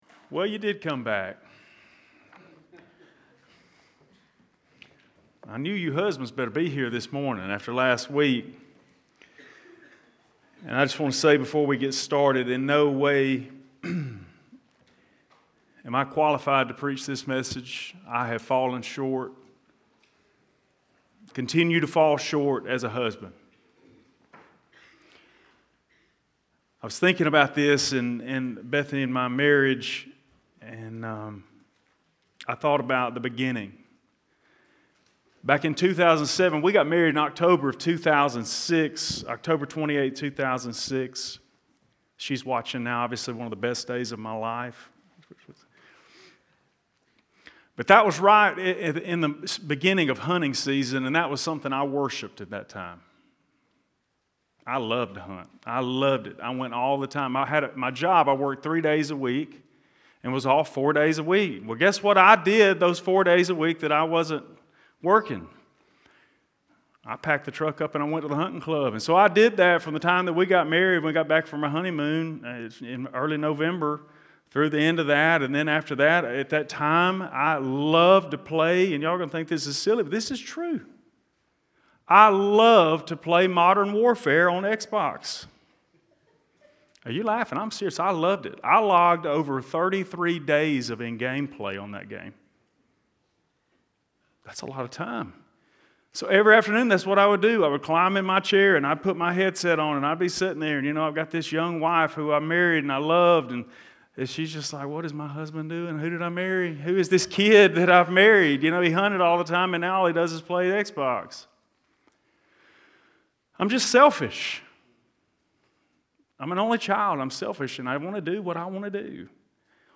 Paul clearly teaches about marriage at the end of Ephesians 5. This is a 2-part sermon on the conduct of husbands and wives in a marriage. This sermon is focused on the husbands.